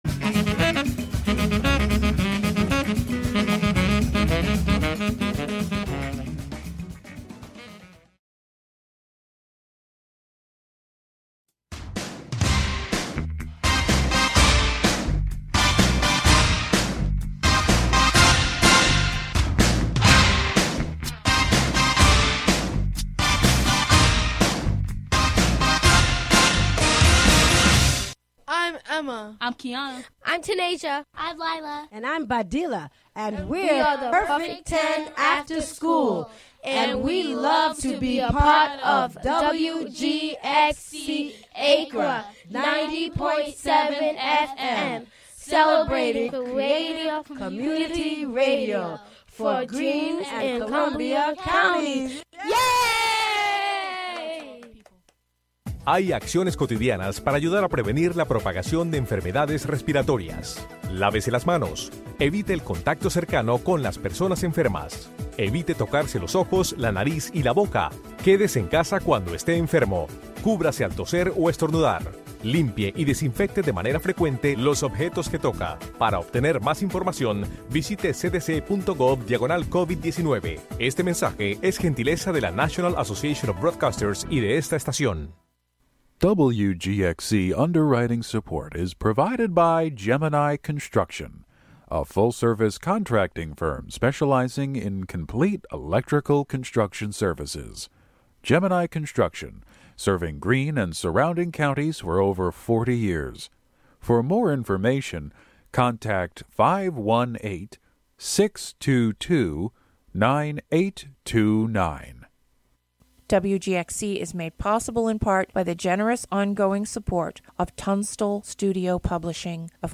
Broadcast live from HiLo in Catskill.